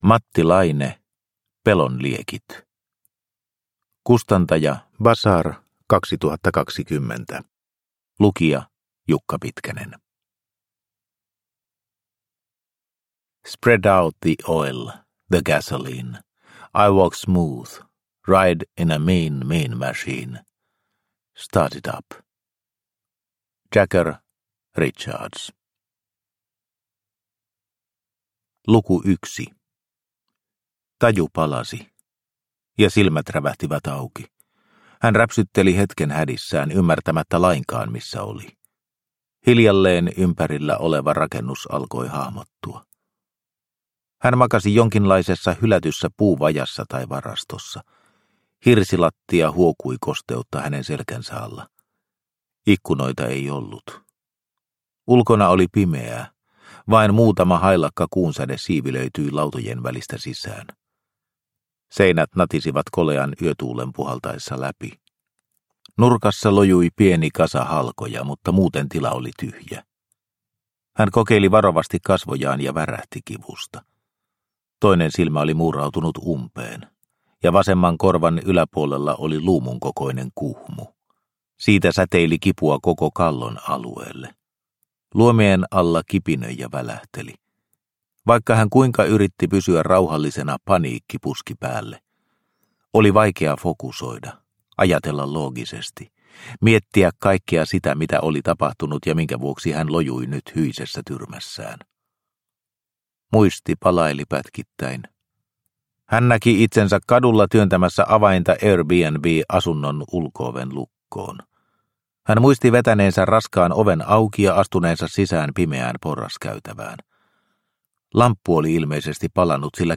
Pelon liekit – Ljudbok – Laddas ner